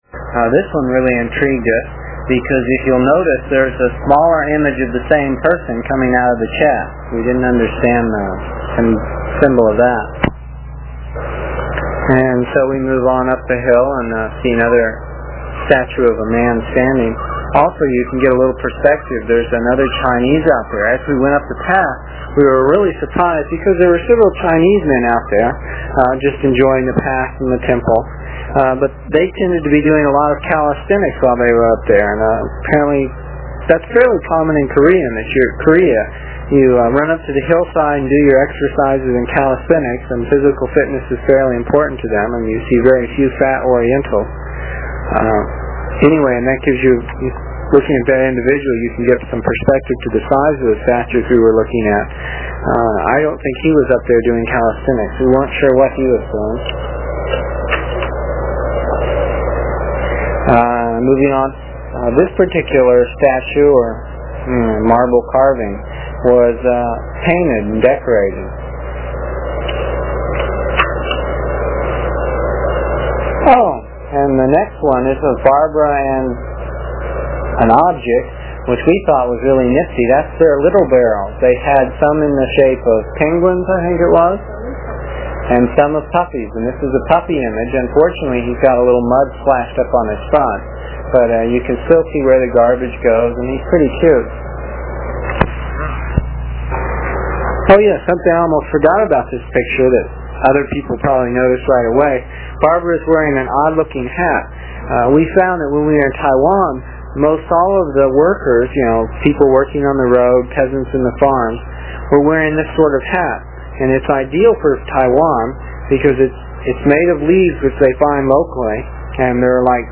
It is from the cassette tapes we made almost thirty years ago. I was pretty long winded (no rehearsals or editting and tapes were cheap) and the section for this page is about seven minutes and will take about three minutes to download with a dial up connection.